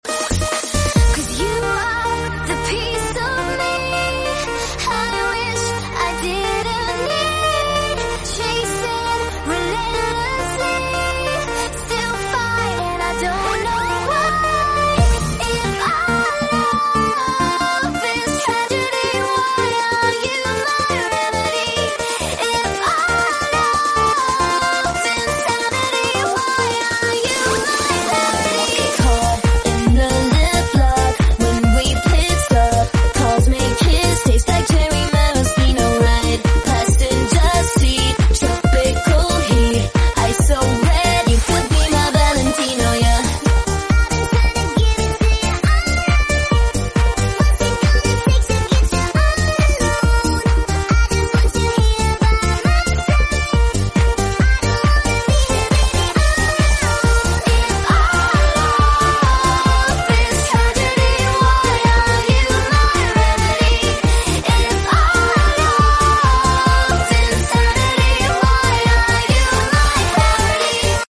lol sound effects free download